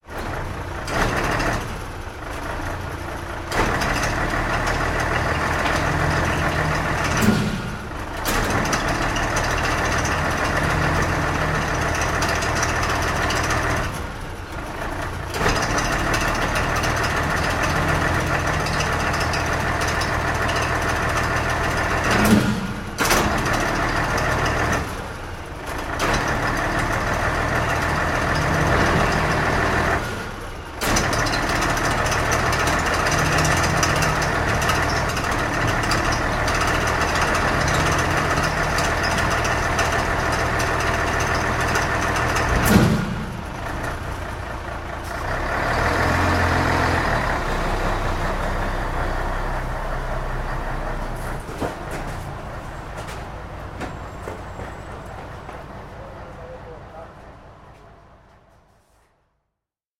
Звуки мусоровоза
Звук подъема мусорного контейнера мусоровозом